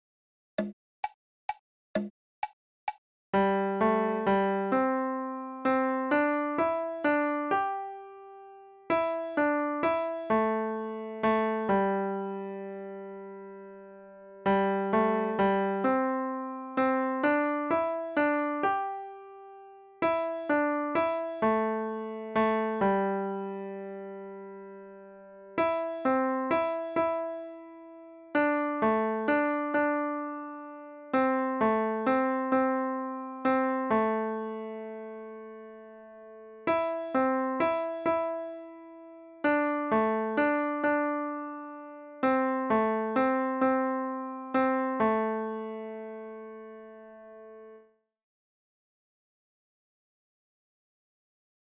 Free Piano Music!